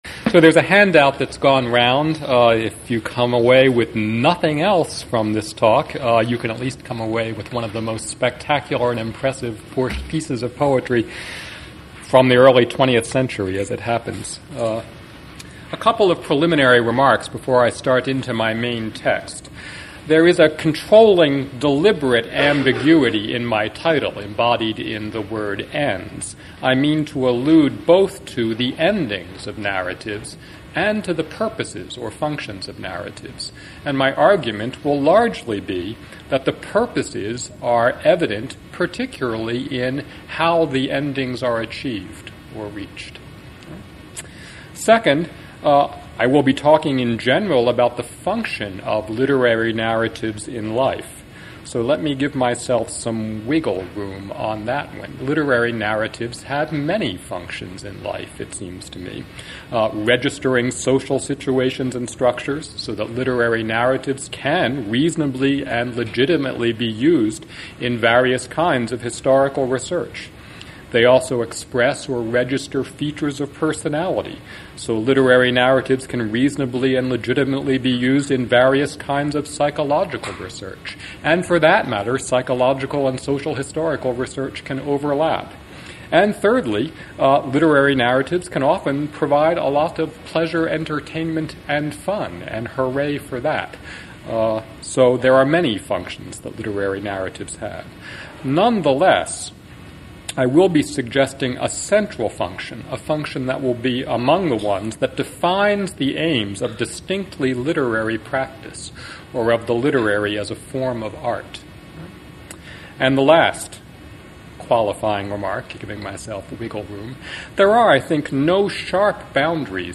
Faculty Lecture
faculty-lecture.mp3